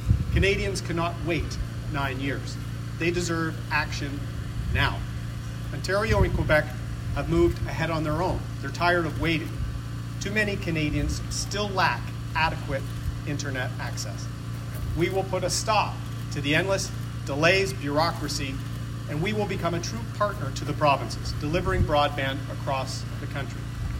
That is one of the pillars of Conservative leader Erin O’Toole’s plan for rural Canada which he revealed Monday afternoon in Belleville.